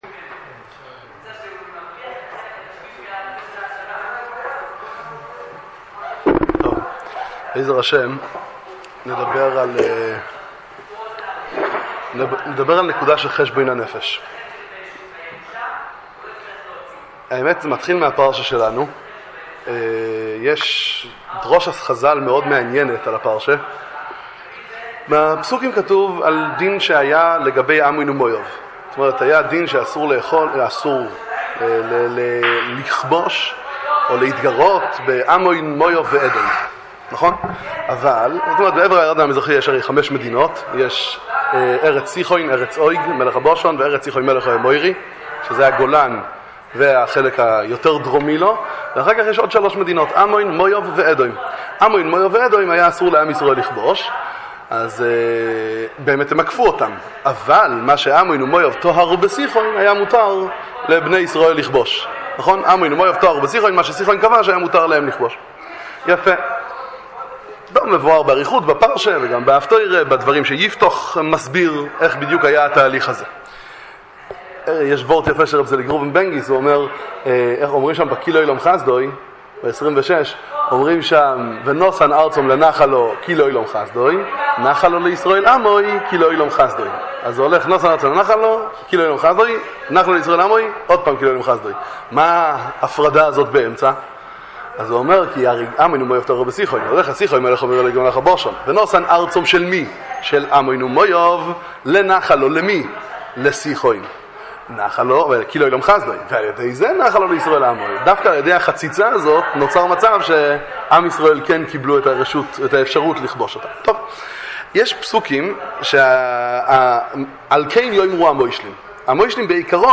שיחת חיזוק לבחורי ישיבת ארחות תורה